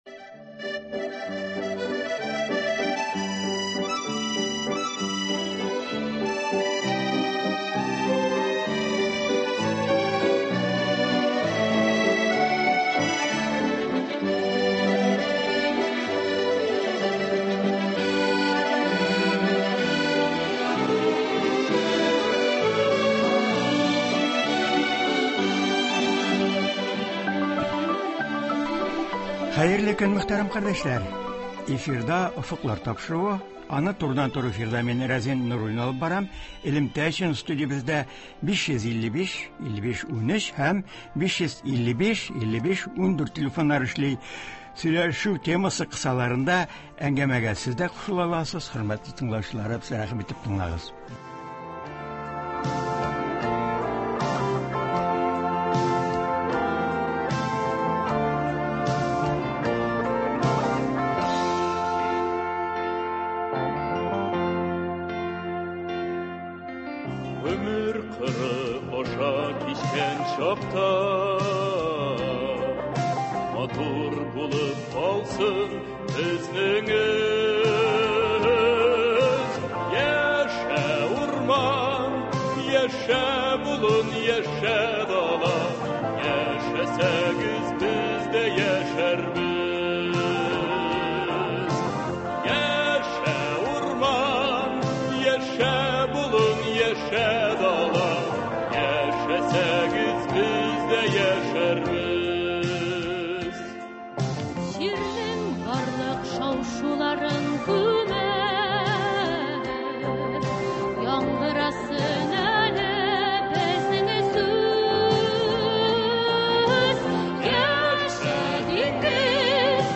Тапшыруда ветеринария фәннәре докторы, профессор, Татарстан Президенты киңәшчесе Фатих Сибагатуллин республикабызда мохитне саклау буенча күрелгән чаралар турында сөйләячәк, актуаль мәсьәләләргә тукталачак, тыңлаучылар сорауларына җавап бирәчәк.